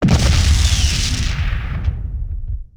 enemymissile.wav